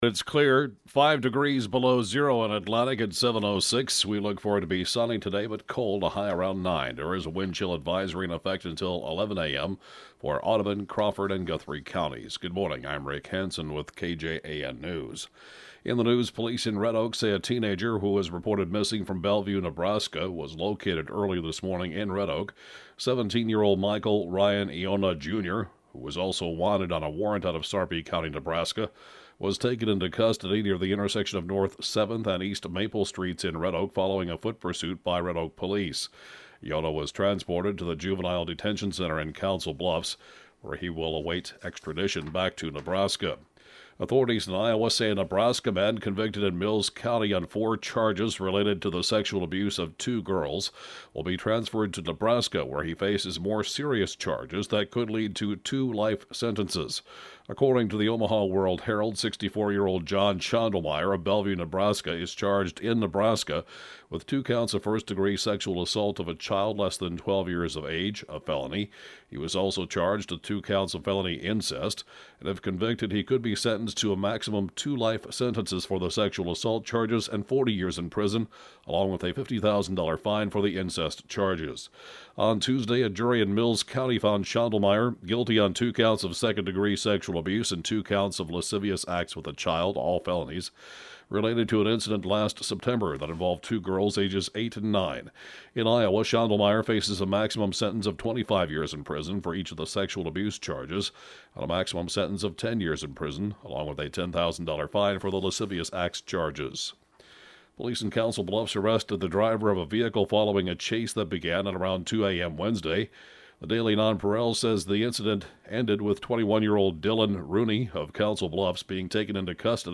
(Podcast) 7-a.m. News & funeral report